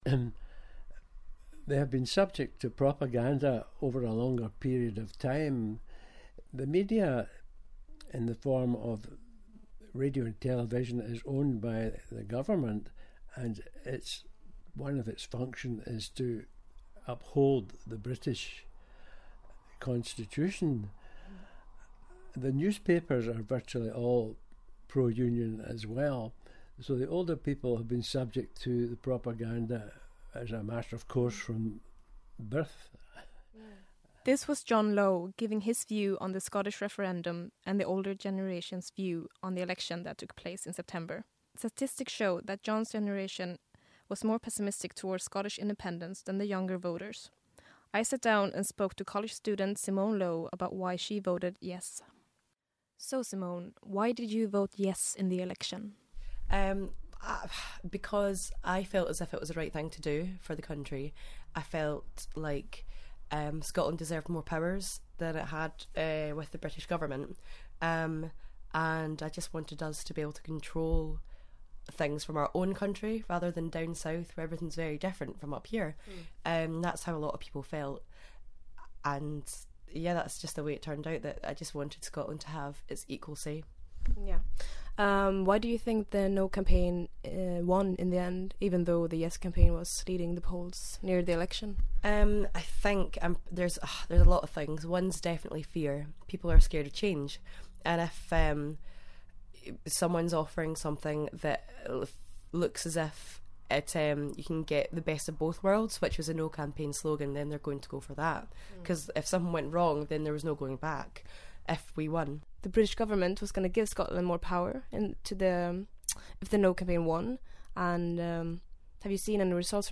Interview: After the Scottish Referendum